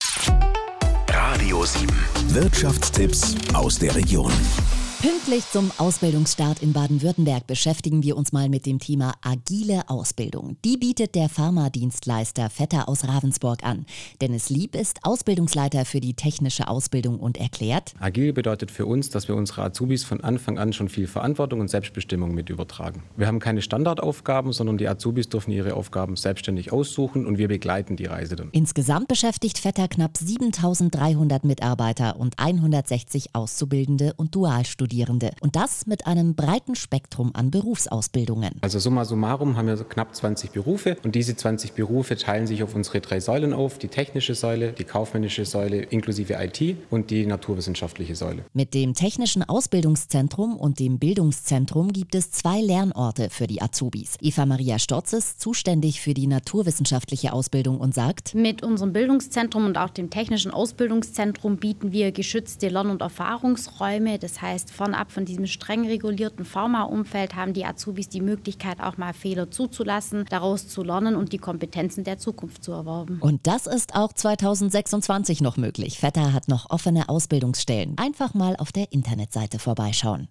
Radio_7_Agile_Ausbildung.mp3